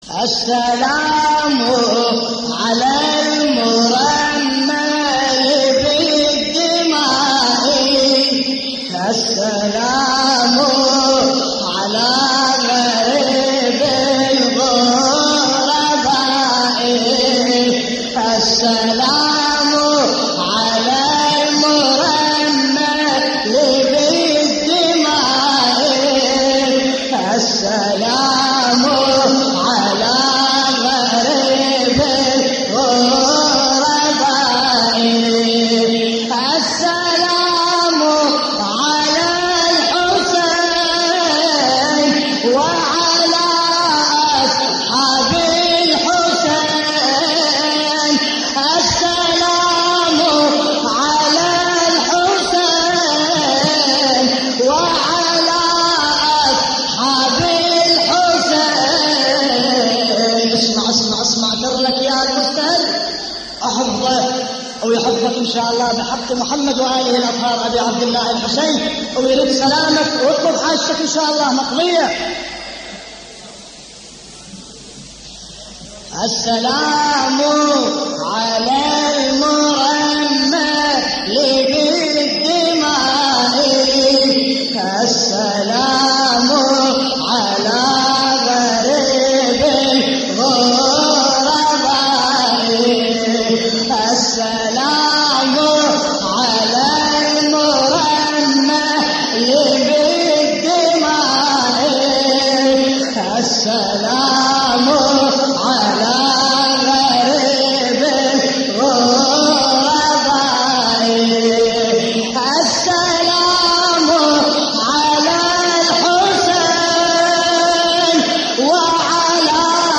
تحميل : السلام على المرمل بالدماء السلام على غريب الغرباء / الرادود باسم الكربلائي / اللطميات الحسينية / موقع يا حسين